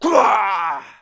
monster / thiefboss2 / dead_1.wav
dead_1.wav